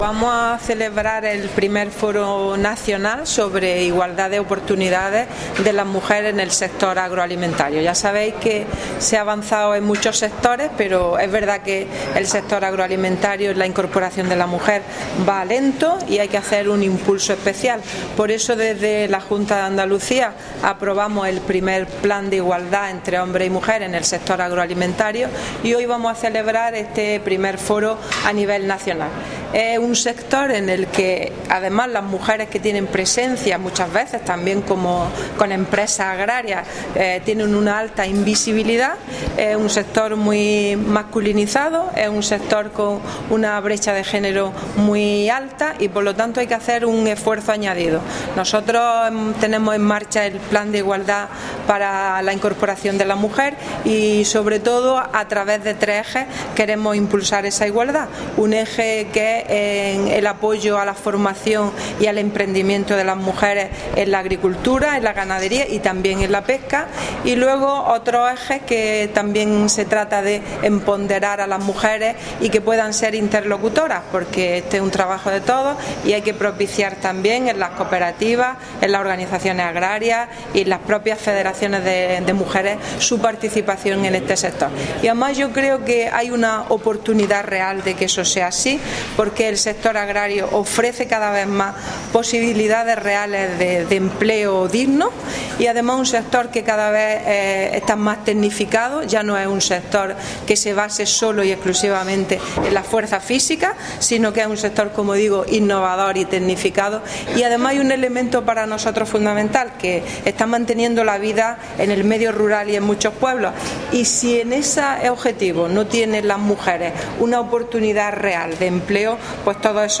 Declaraciones de Carmen Ortiz sobre el I Foro Nacional Business Agro ‘Mujeres agroprofesionales. Por la igualdad de oportunidades’